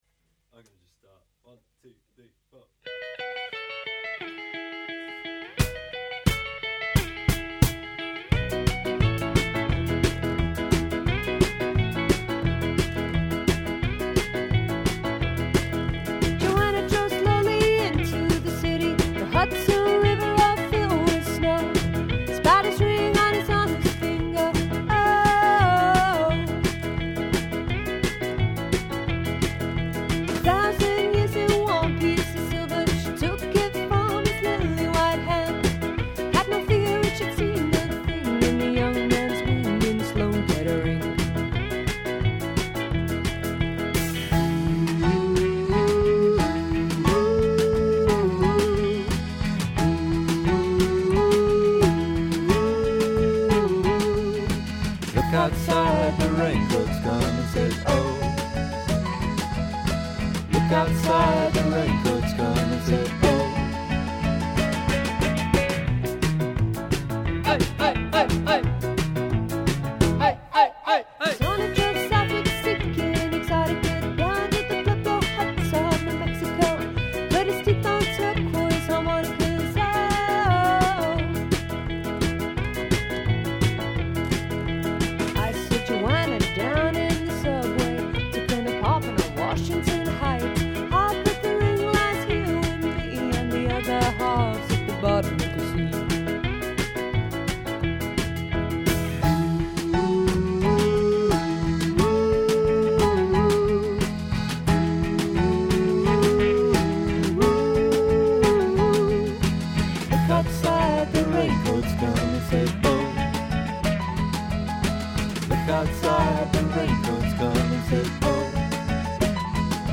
• Classic pop band delivering music from the 50s to the 90s